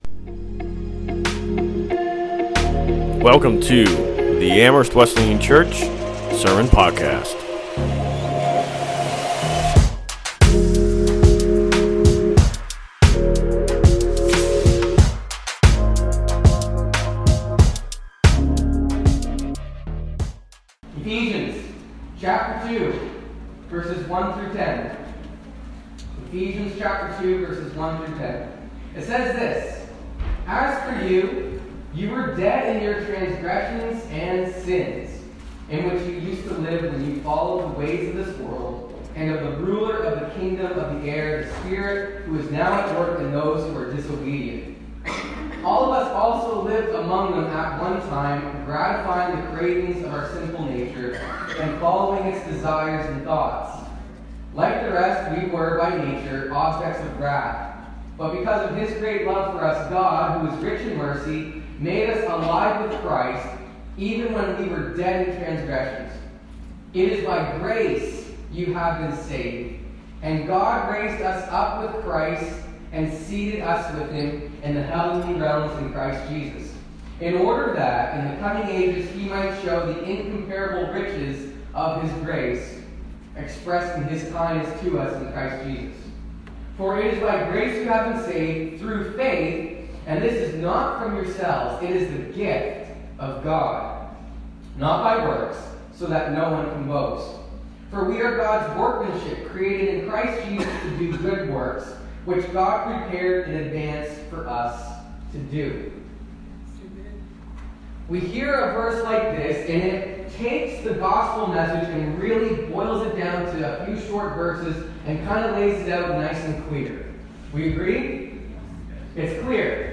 Sermons | Amherst Wesleyan Church